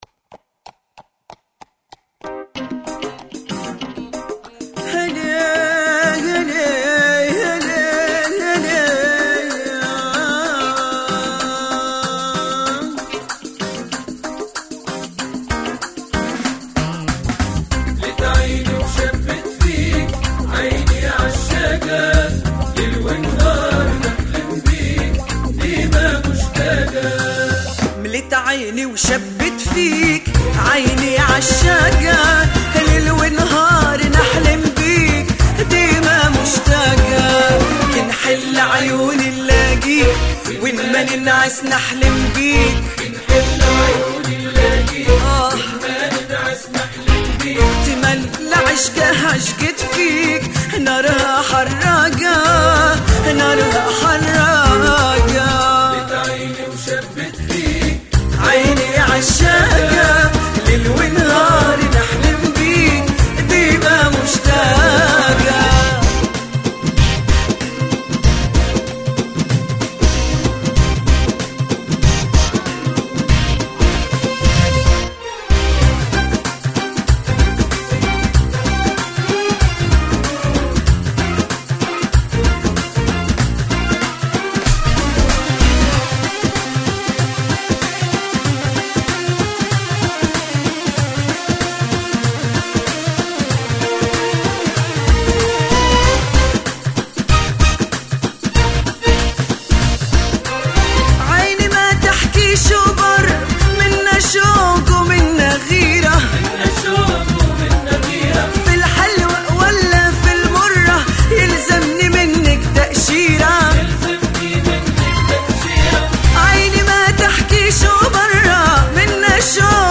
أغنية